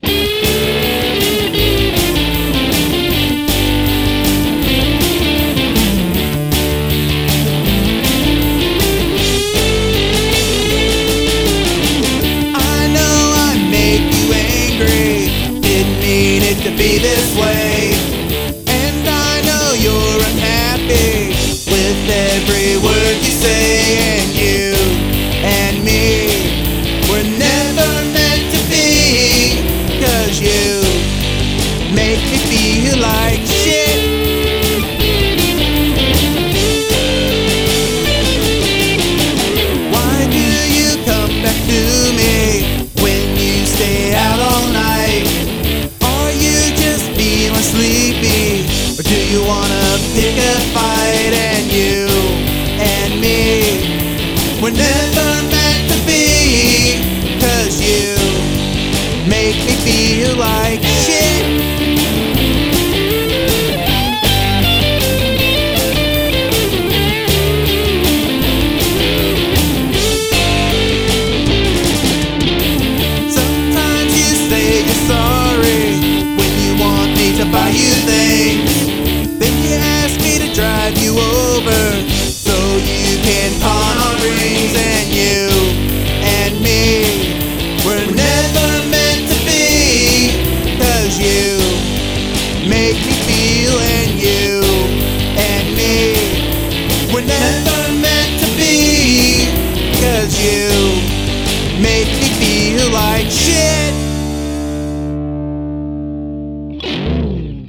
Written and recorded in an evening.
The backing vocals sound great!!!
Love the punky vibe to it too!